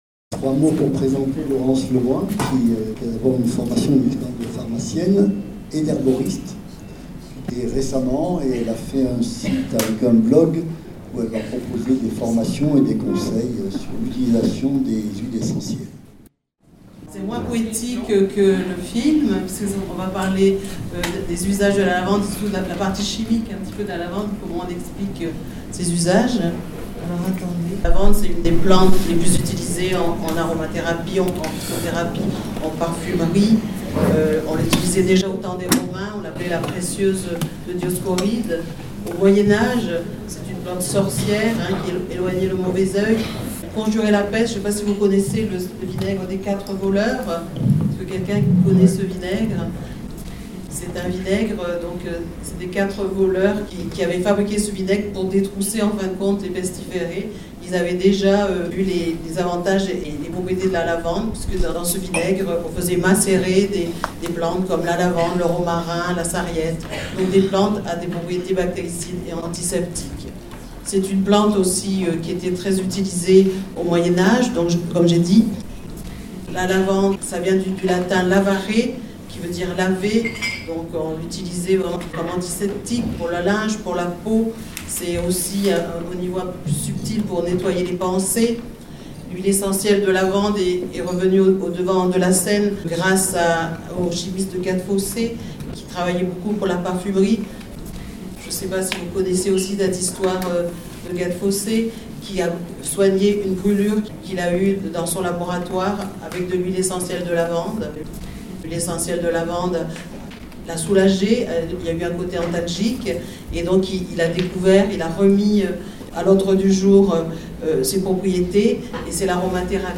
Voici également un extrait audio de mon intervention sur les usages de la lavande qui a été enregistré lors du débat durant des rencontres Ecologie au quotidien qui se sont déroulées à Die sous le thème « Osons la fraternité pour mieux vivre ensemble « . https
Ne pas oublier que l’enregistrement s’est déroulé en direct en pleine salle communautaire à Die….